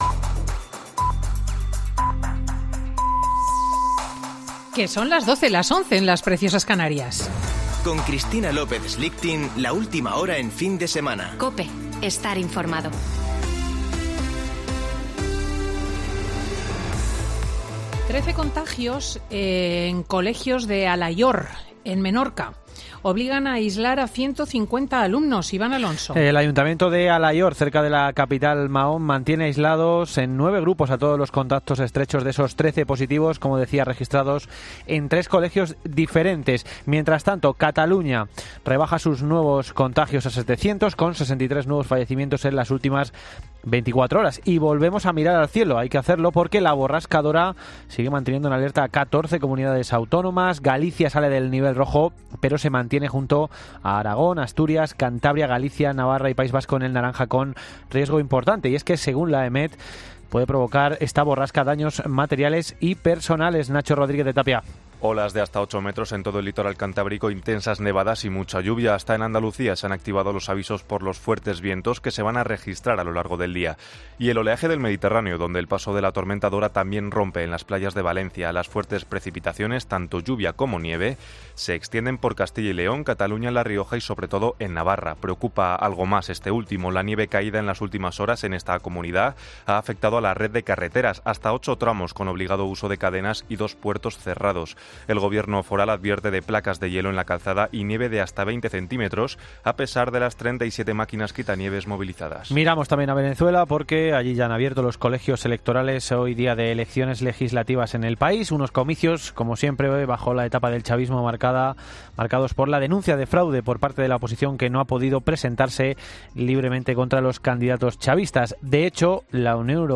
Boletín de noticias COPE del 6 de diciembre de 2020 a las 12.00 horas